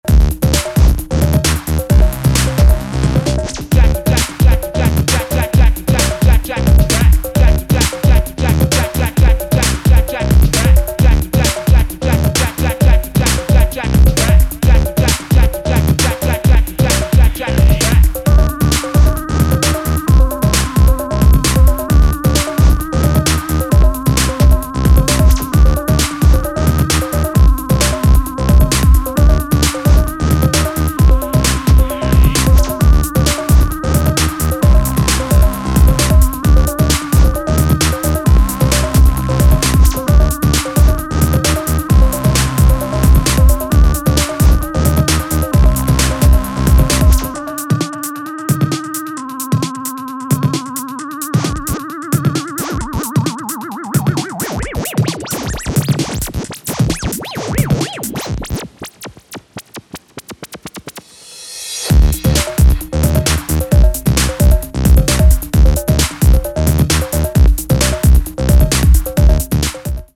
スリージーにピッチを落としアシッド増量を図った